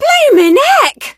rosa_hit_03.ogg